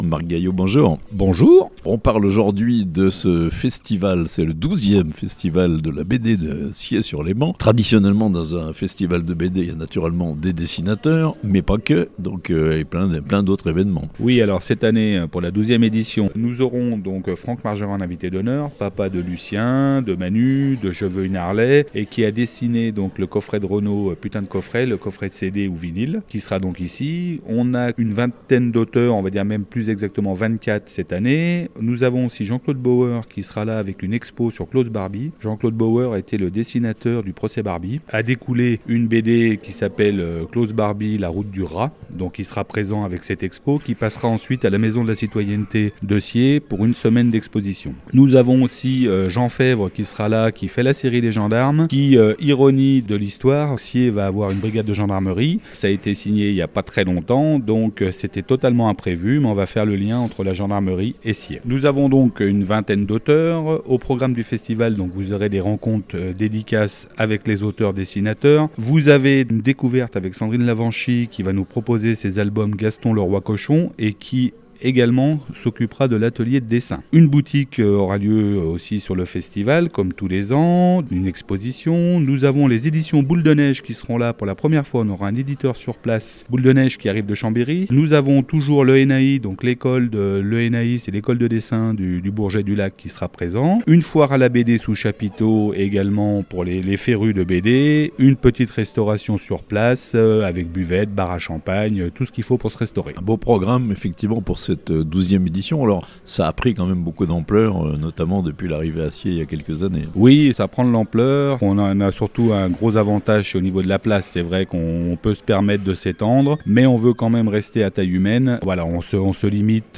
12ème festival de la BD à Sciez-sur-Léman (interview)